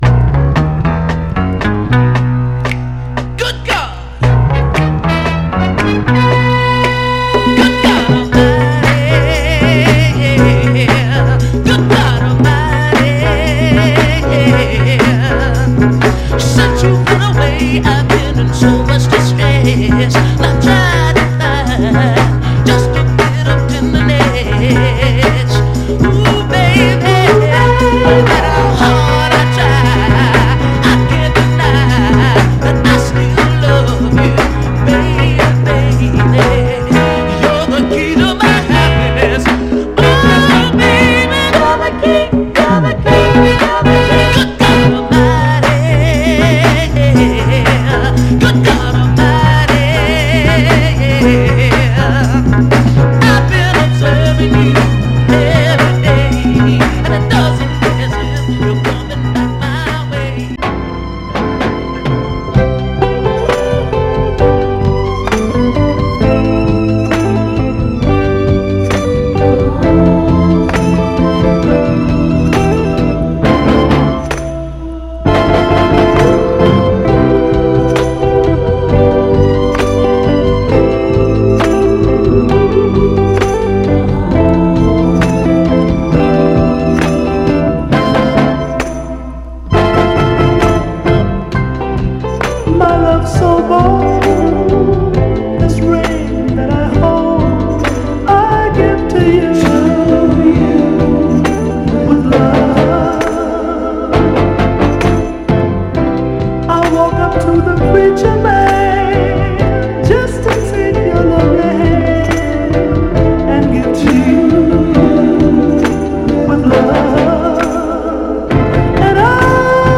両面イケてるダブルサイダーな7インチ・シングルです。
盤はいくつか目立つ表面スレ箇所ありますが、グロスが残っておりプレイ良好です。
※試聴音源は実際にお送りする商品から録音したものです※